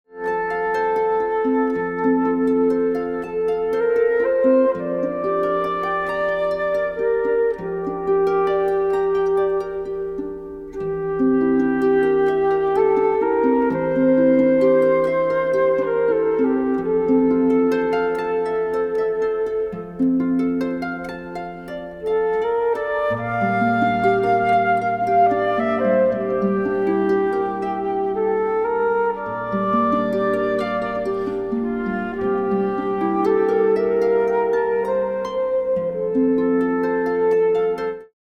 Is a collection of romantic and exotic pieces
Consisting primarily of harp solos